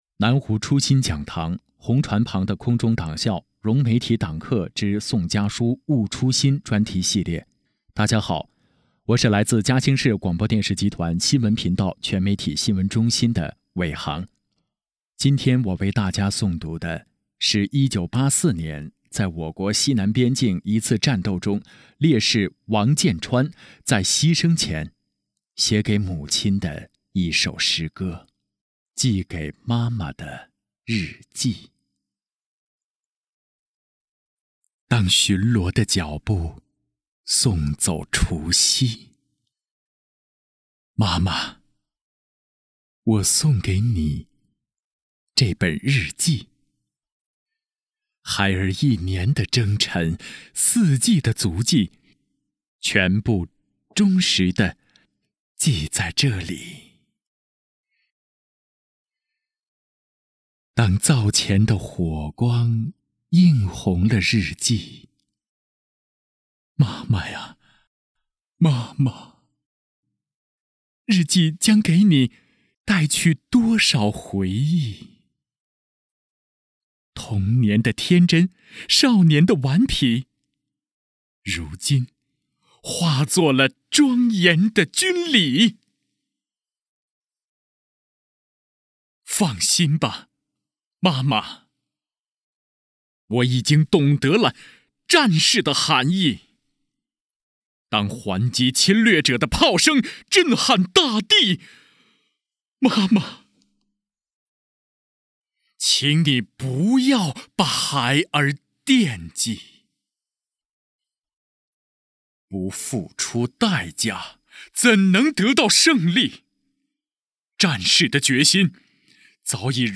配音主播介绍